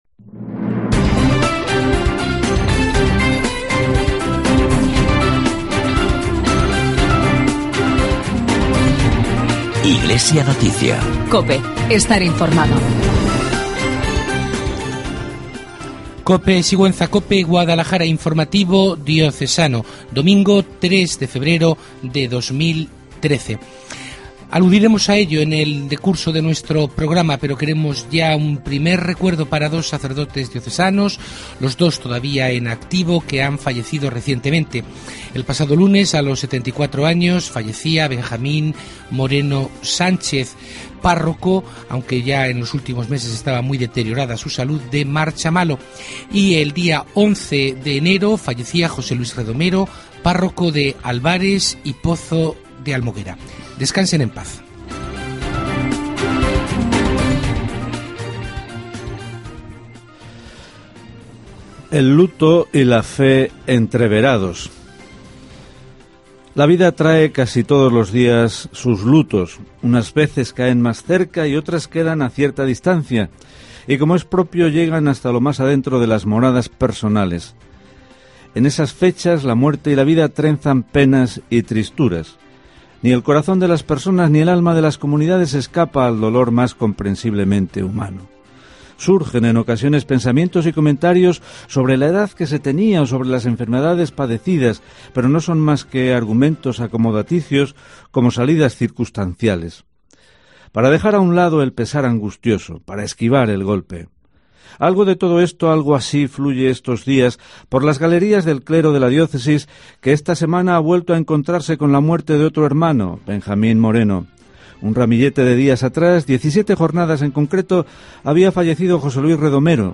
AUDIO: Informativo Diocesano de Sigüenza-guadalajara